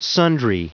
Prononciation du mot sundry en anglais (fichier audio)